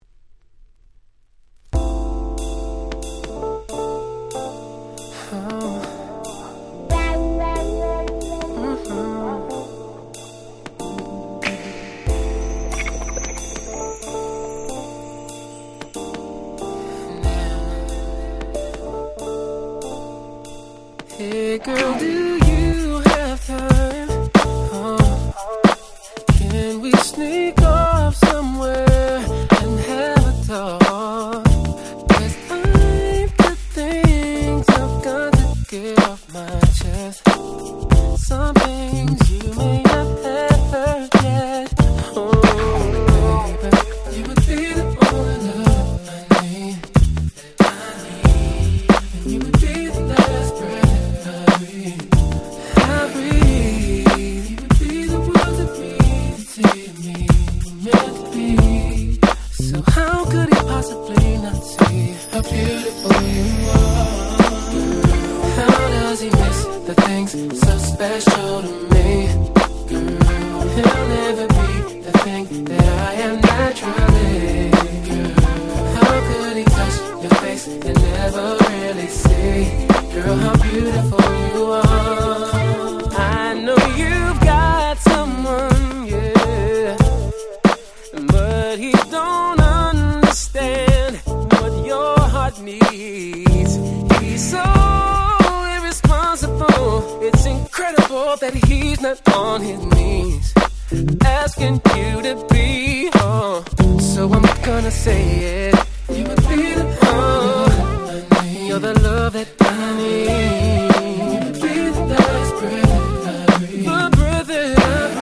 03' Very Nice Neo Soul / R&B !!